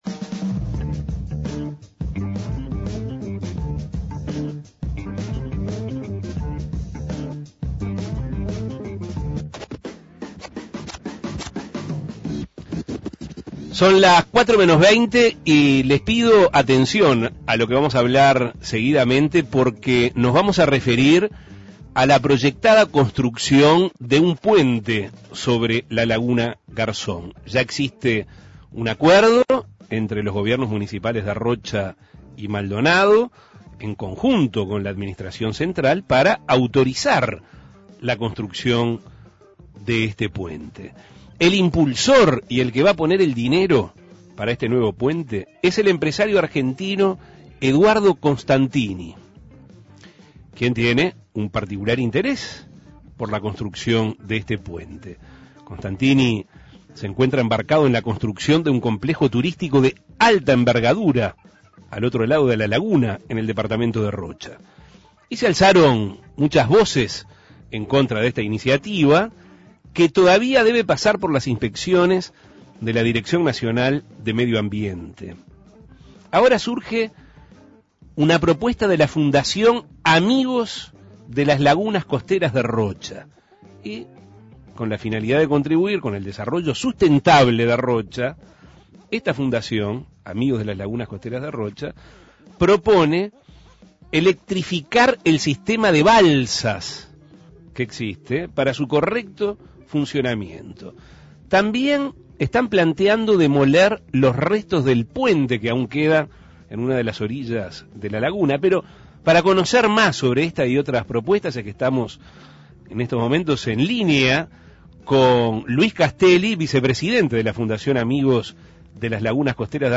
Entre ellas, se destacan electrificar el sistema de balsas y demoler los restos de puente que aún quedan en una de las orillas de la Laguna. Escuche la entrevista.